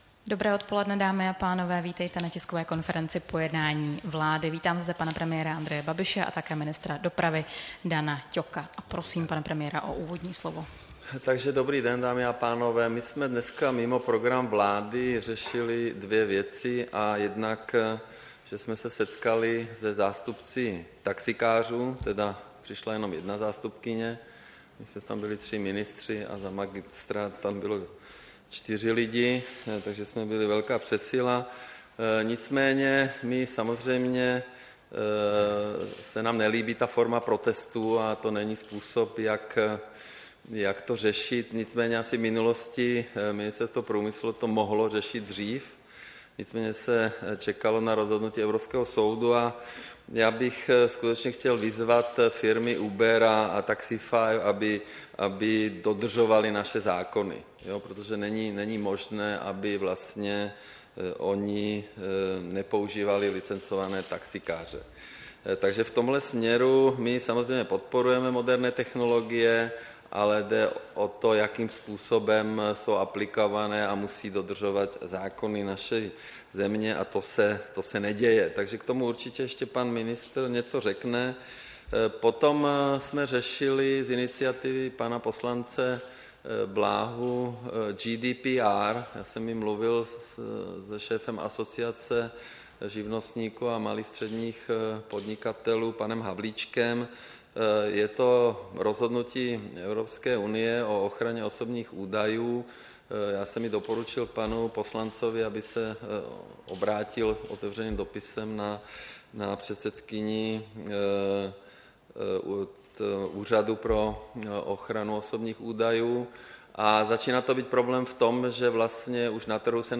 Tisková konference po jednání vlády, 14. února 2018